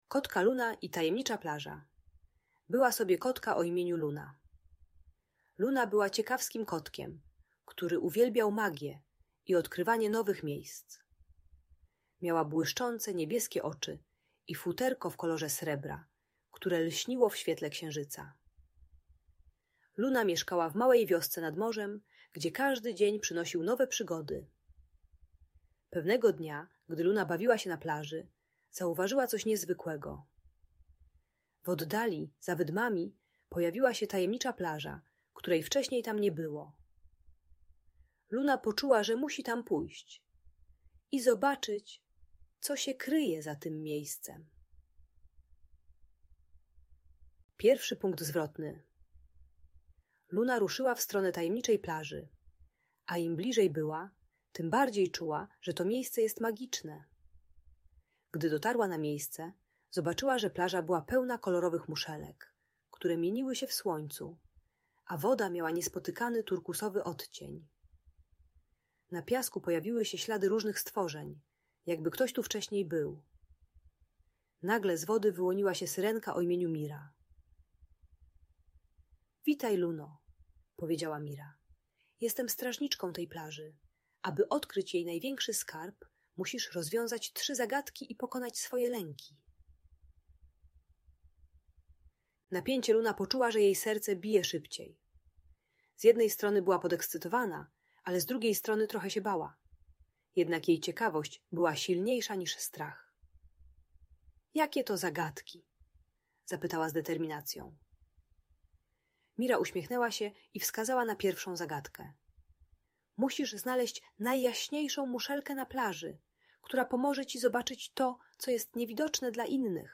Kotka Luna i Tajemnicza Plaża - Audiobajka dla dzieci